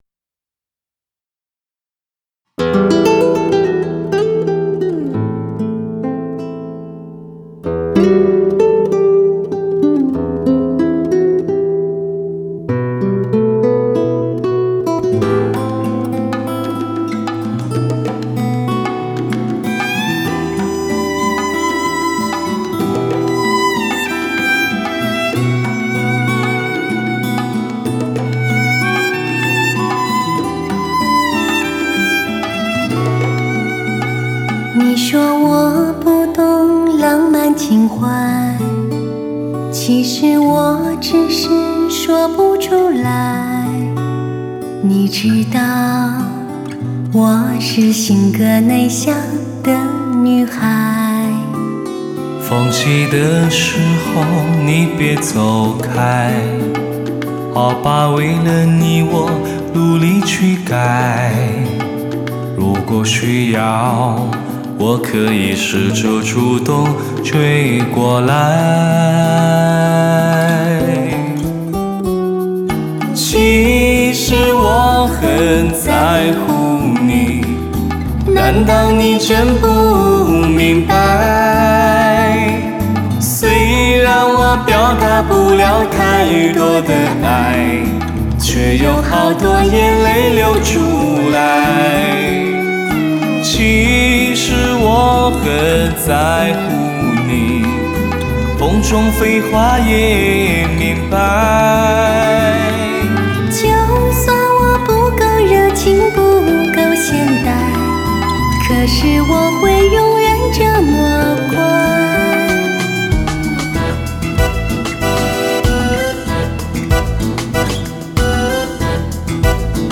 开创革命性的STS+SRS全方位环绕Hi-Fi_AUTO_SOUND专业碟
STS+SRS(●) Surround7.1三维环绕Hi-Fi多音色高临场感效果碟